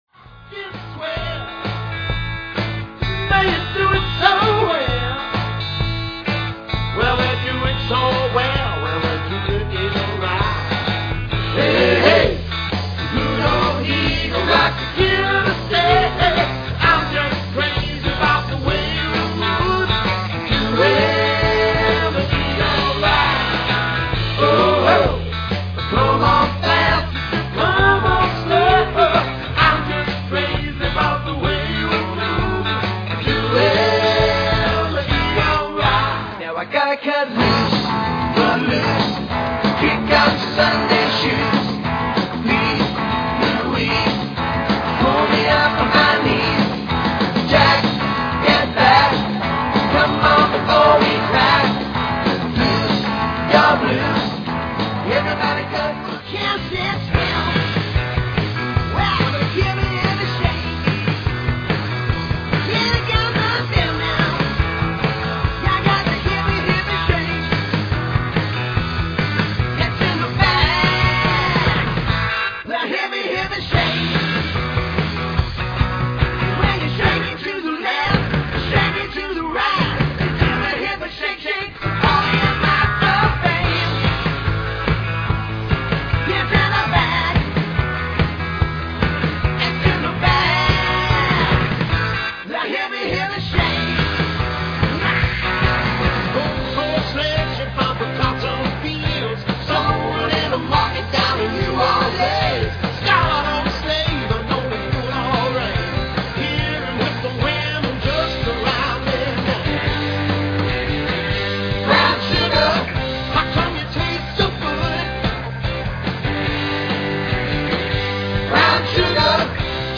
CLASSIC ROCK
traditional powerhouse trio
performing purely live music